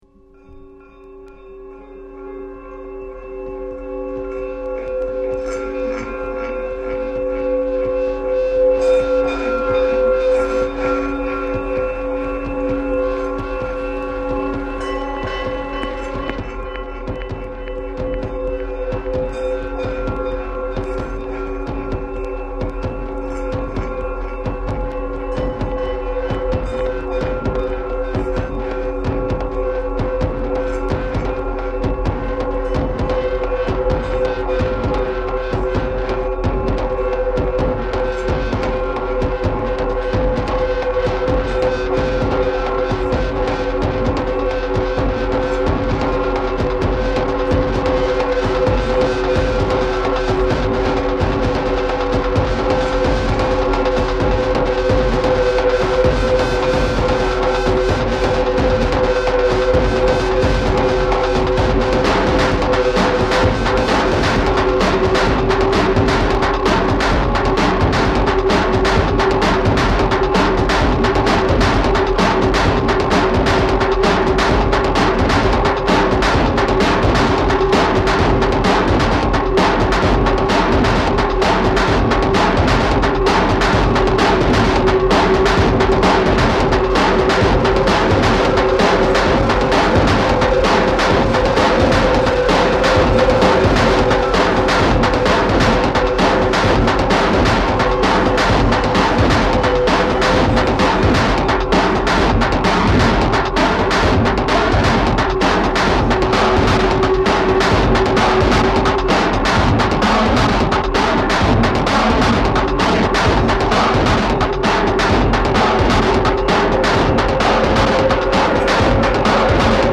LP]リズミック・ノイズテクノミニマル